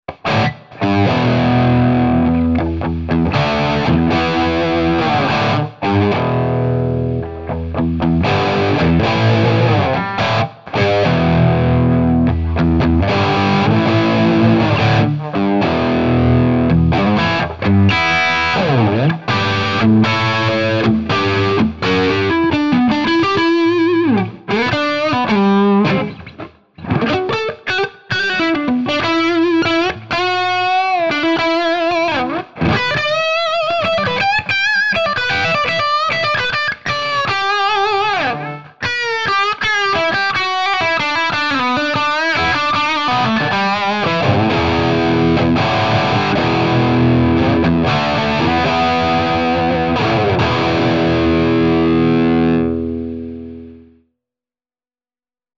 Plexi sa mi tam tiez velmi lubi, asi aj viac, ako ostatne veci:
Je to hrane na HB Gibson 498T / 490R.
GR3plexi.mp3